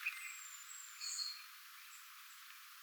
erikoinen äänisarja oletettavasti sinitiaiselta
erikoinen_aanisarja_oletettavasti_sinitiaiselta.mp3